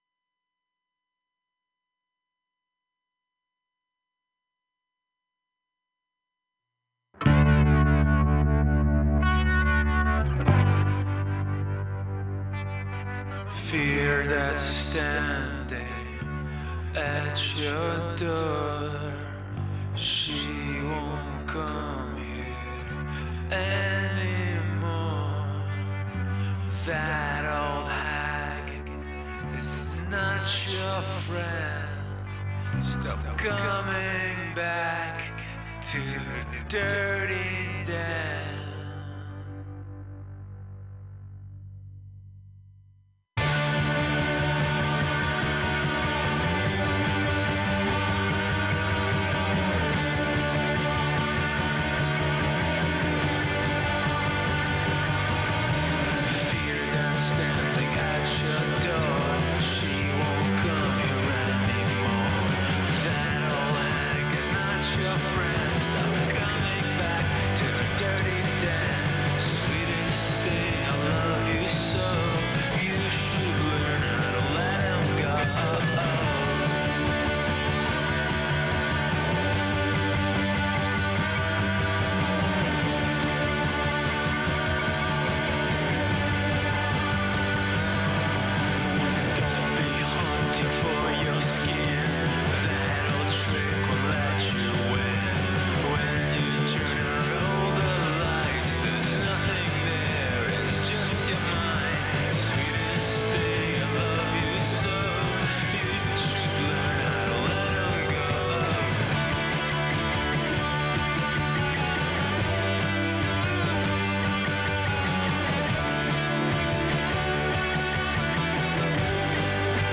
Each broadcast features candid conversations with both professional and aspiring artists, uncovering the heart of their creative process, the spark of their inspiration, and the journey that brought them into the art scene. From painters and sculptors to musicians and writers, we celebrate the richness of artistic expression in our community and beyond.